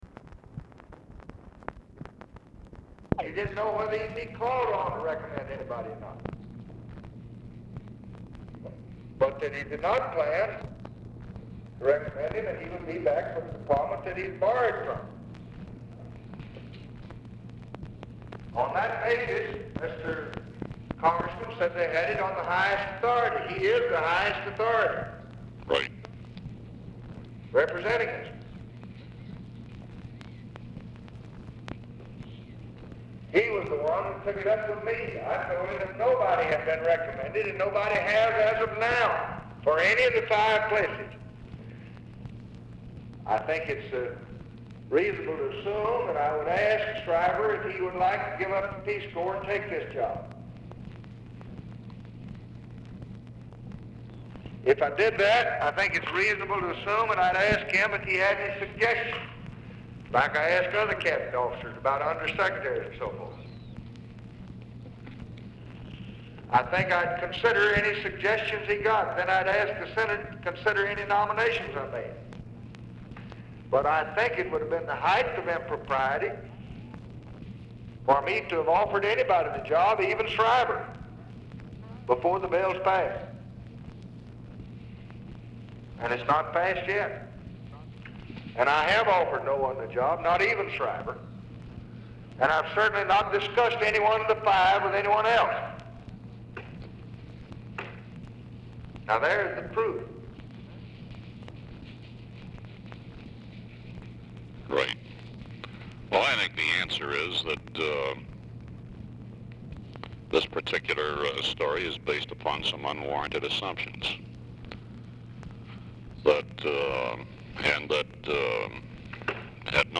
Telephone conversation # 4881, sound recording, LBJ and GEORGE REEDY, 8/11/1964, 10:55AM
LBJ SPEAKS WITH UNIDENTIFIED PERSON IN HIS OFFICE DURING CALL
Format Dictation belt